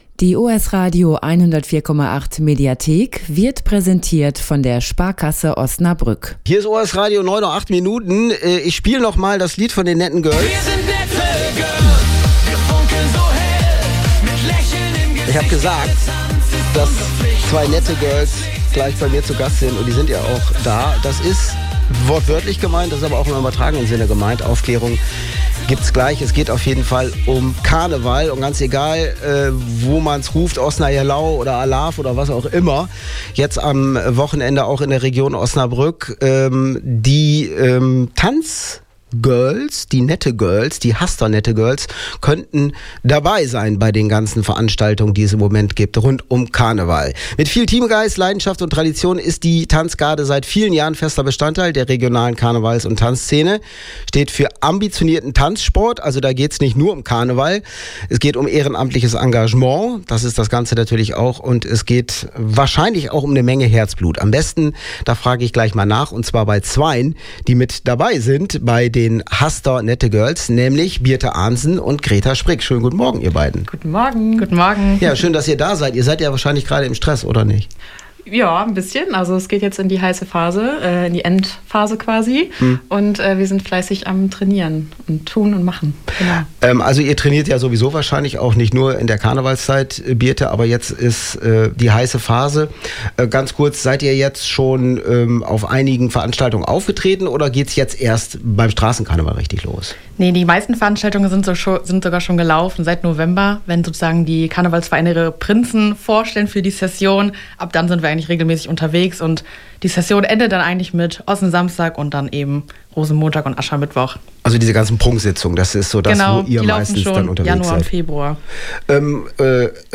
Ein Blick hinter die Kulissen einer Garde, die den Ossensamstag mit Herzblut lebt. 2026-02-11 OS-Radio 104,8 Mitschnitt Interview Haster Nette Girls Tanzgarde Download